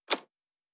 Sci-Fi Sounds / Mechanical / Device Toggle 19.wav
Device Toggle 19.wav